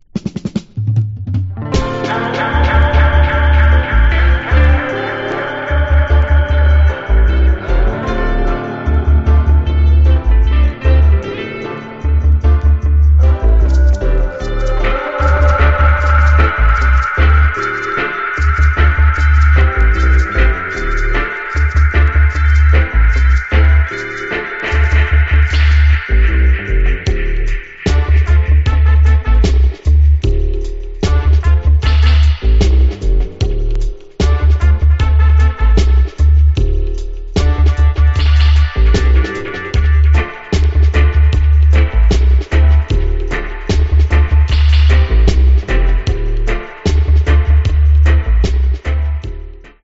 Genre: Dub Reggae.